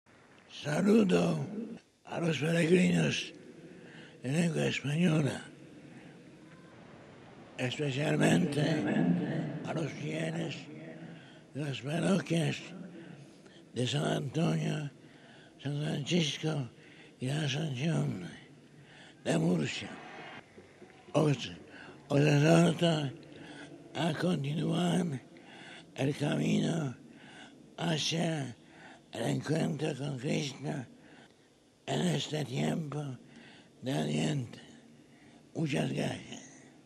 Después del rezo del Ángelus, y de haber pedido a la María, “mujer eucarística y Virgen del Adviento” su intercesión para “prepararnos a acoger con dicha a Cristo”, el Santo Padre saludó a los fieles y peregrinos presentes en la plaza de san Pedro en distintas lenguas.